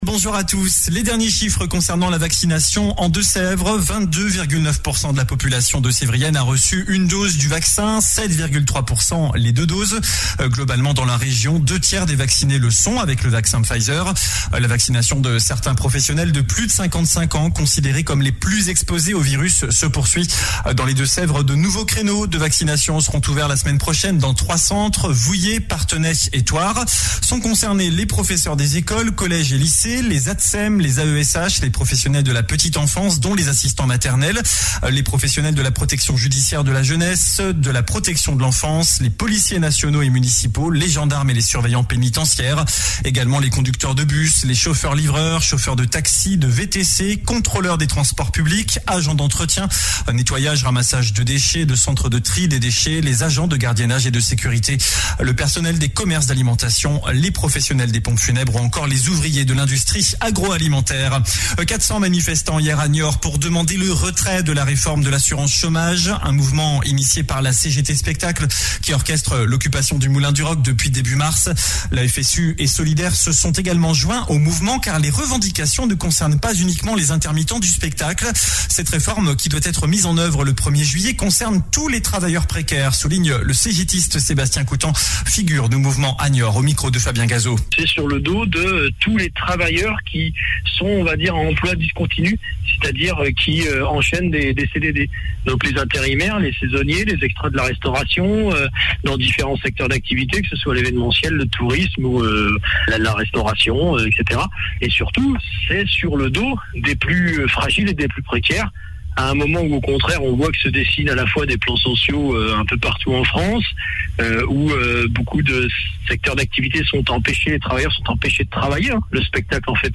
JOURNAL DU SAMEDI 24 AVRIL